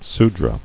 [Su·dra]